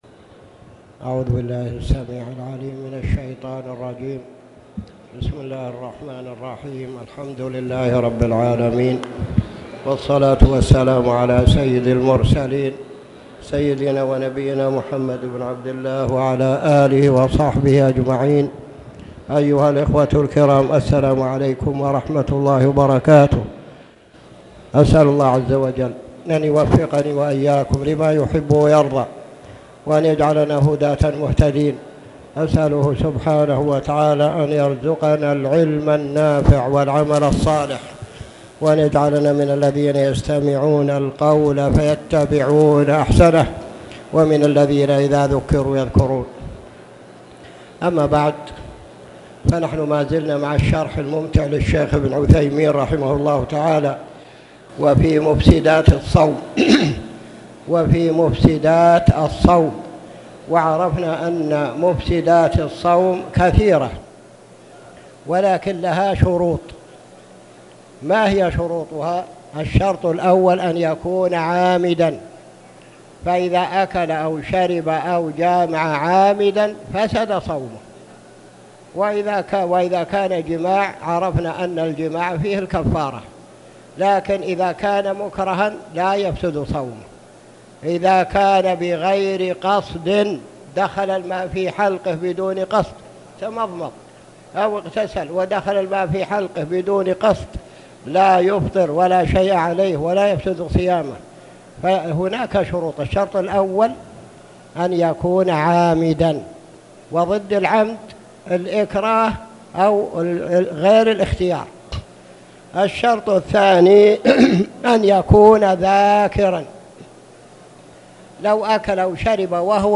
تاريخ النشر ٢٨ رجب ١٤٣٨ هـ المكان: المسجد الحرام الشيخ